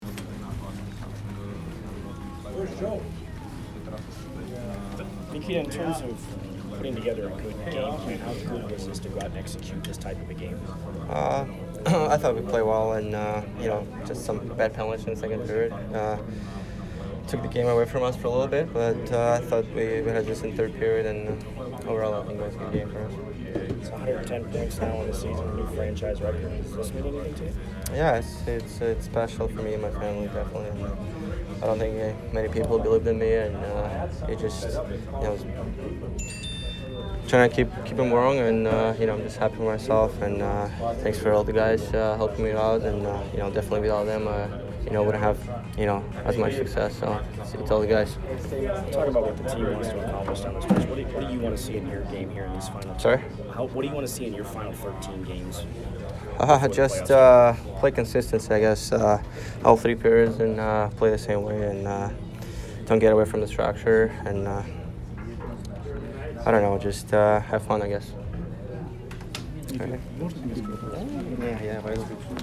Nikita Kucherov post-game 3/9